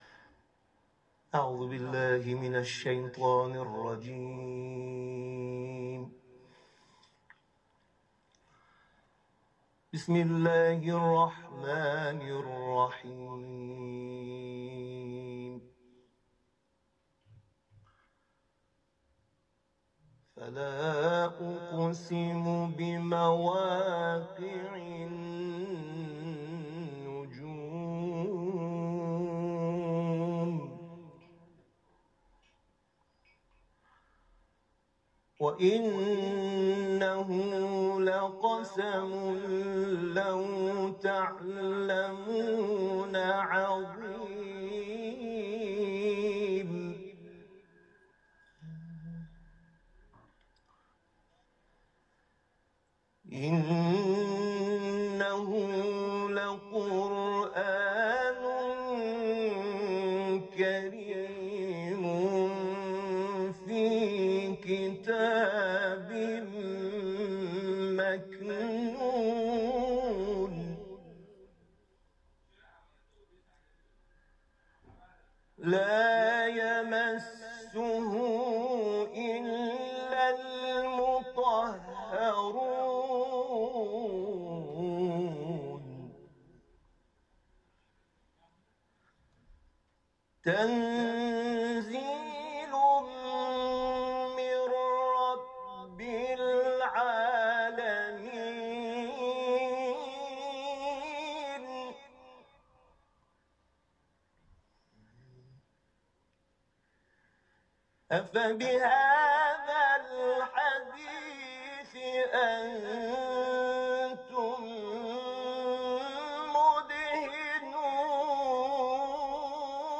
تلاوت ، سوره واقعه